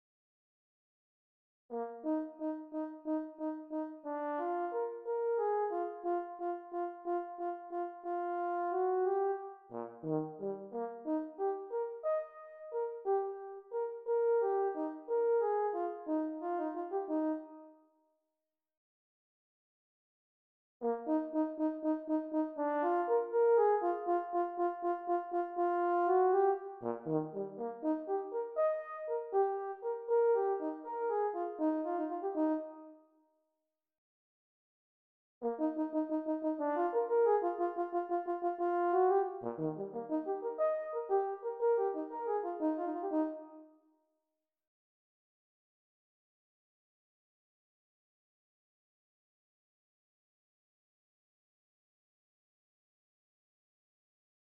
It starts with the 4th, then the 3rd, then the 2nd and finally the 1st.
I did three versions: slow, medium and actual performance tempo.
mozart-horn-m3.mp3